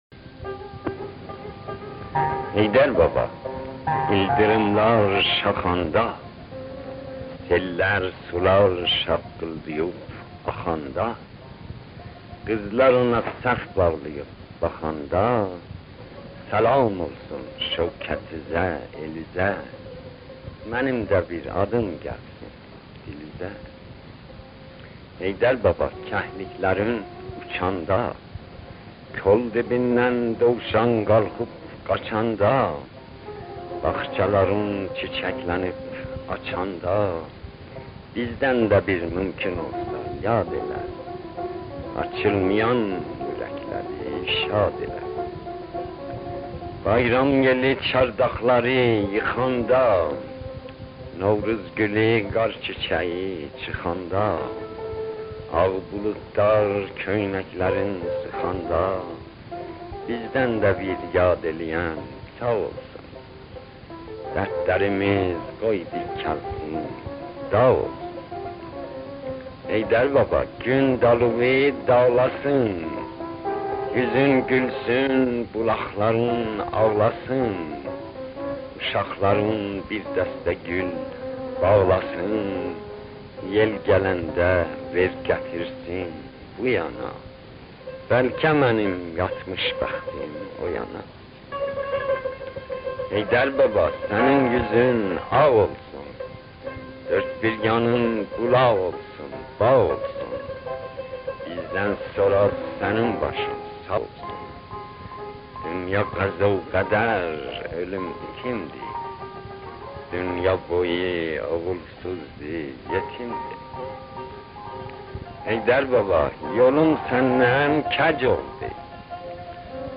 "Heydər Babaya salam" Şəhriyarın öz dilində
Şəhriyar təxəllüsü ilə yazan böyük Azərbaycan şairi Məhəmmədhüseyn Təbrizi məşhur "Heydər babaya salam" şeirində Novruz bayramı ənənələrini də qeyd edir. Bayram günlərində şairin həmin şeirini öz dilindən dinləyək.